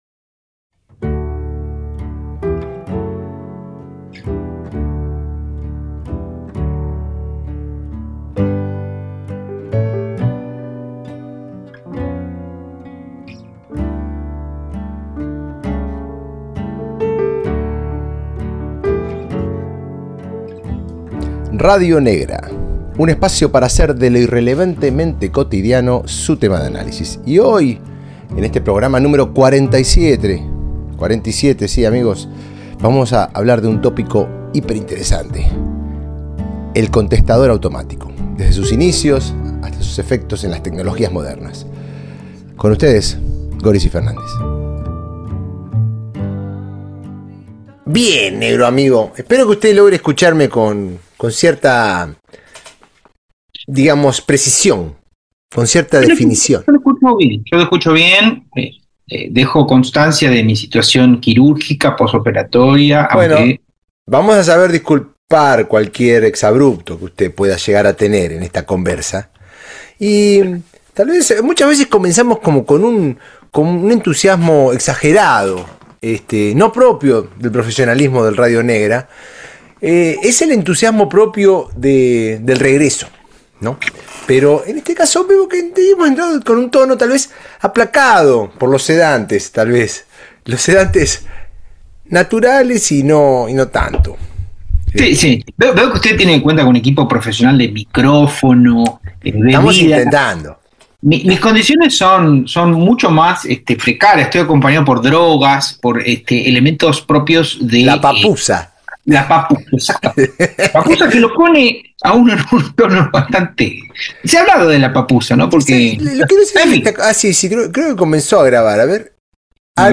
A través de charlas, recuerdos y referencias, los Negra exploran cómo el contestador automático inauguró una nueva forma de comunicación: ni inmediata ni completamente ausente, un territorio intermedio donde la voz queda suspendida en el tiempo.